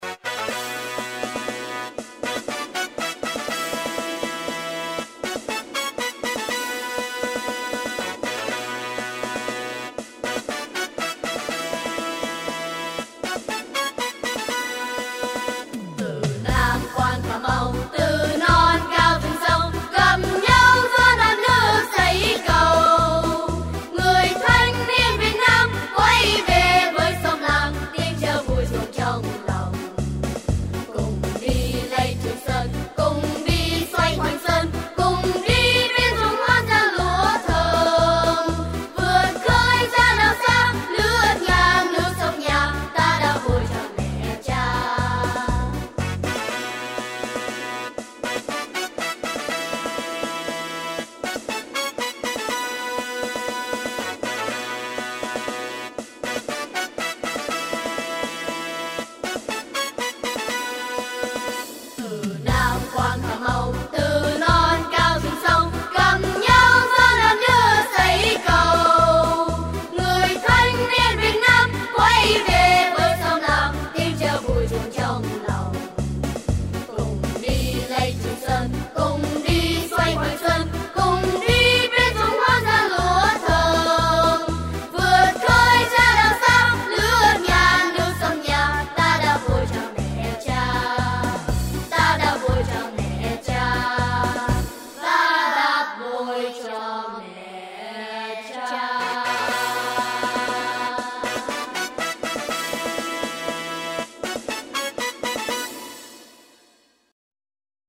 Hơp ca